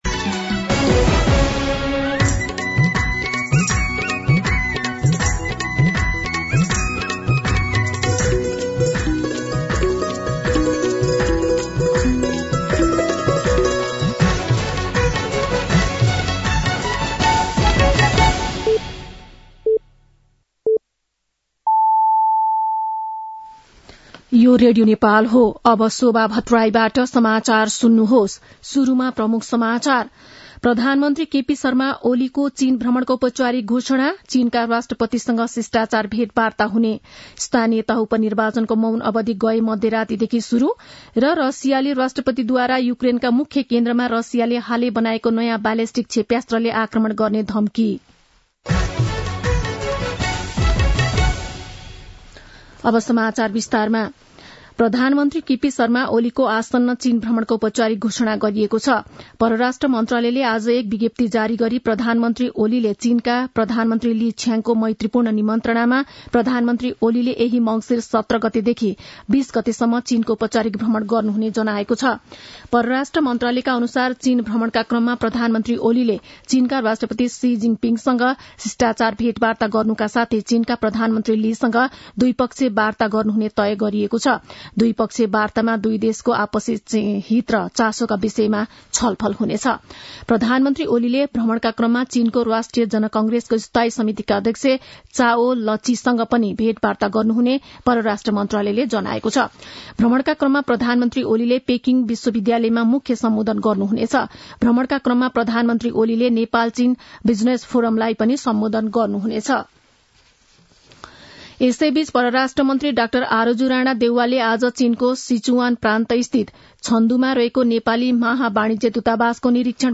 दिउँसो ३ बजेको नेपाली समाचार : १५ मंसिर , २०८१
3-pm-Nepali-News-1-1.mp3